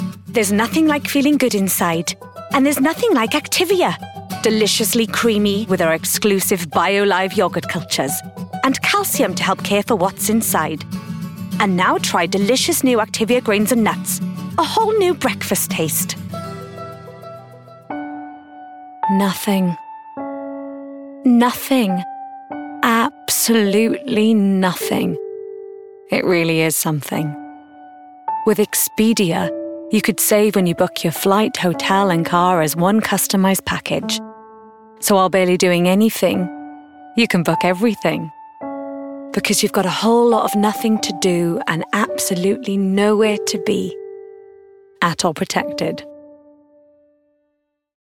20s-40s. Female. Welsh.